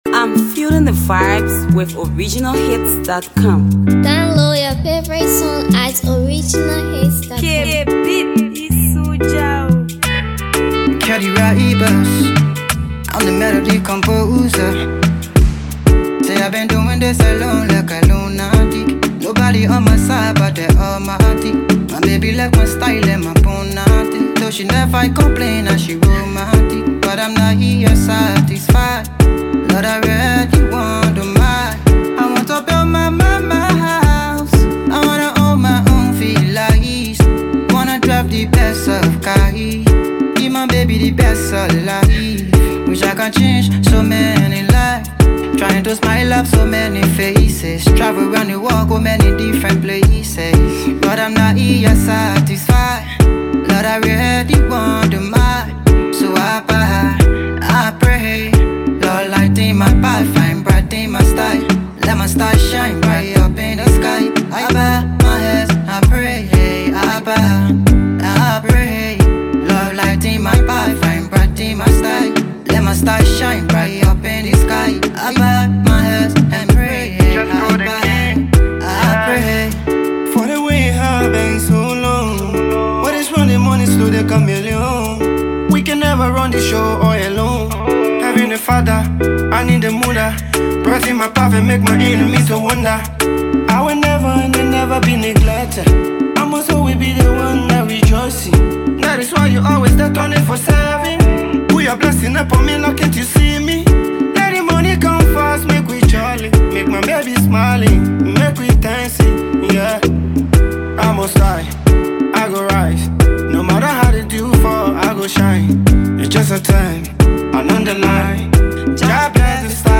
two versatile artists sharing MIC
Afro Fresh vibing tune